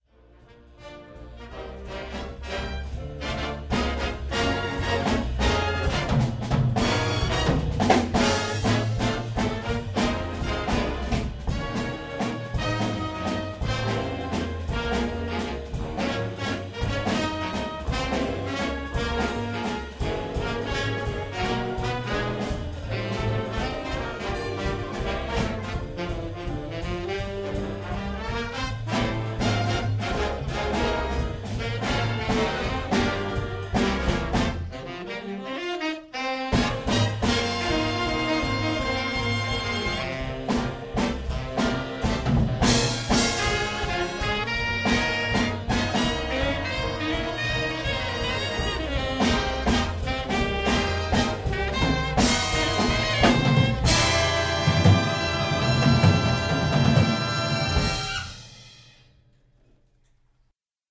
Hofner 60's semi hollow bass with CTC epoxy coating
"I was playing through an Avalon Vt-737SP preamp.